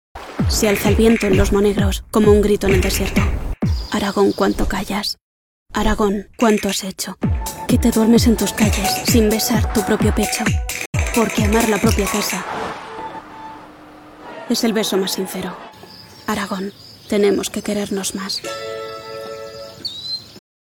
une voix off espagnole lumineuse et captivante – aiguë, vivante et polyvalente – idéale pour les publicités, l'e-learning et la narration.
Promotions
Microphone : Neumann TLM 102.